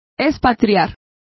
Complete with pronunciation of the translation of expatriated.